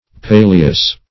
Search Result for " paleous" : The Collaborative International Dictionary of English v.0.48: Paleous \Pa"le*ous\ (p[=a]`l[-e]*[u^]s), a. [L. palea chaff.]